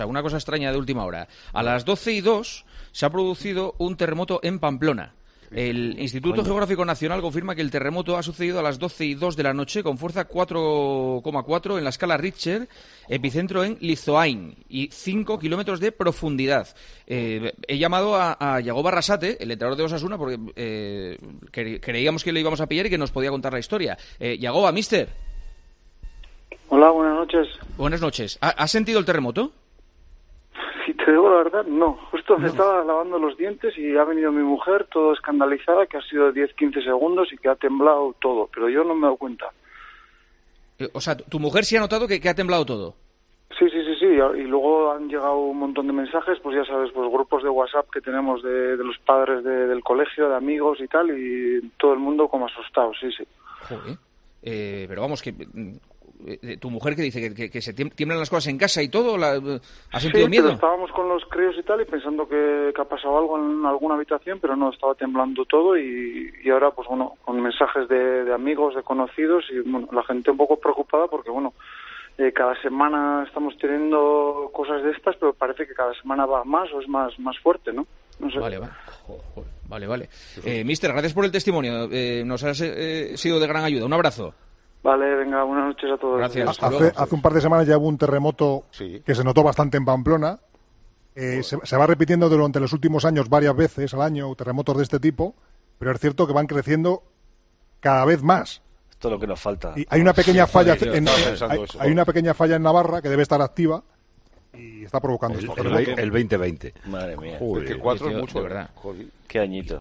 Jagoba Arrasate entró en directo en El Partidazo con Juanma Castaño y Jony lo ha comentado esta mañana en rueda de prensa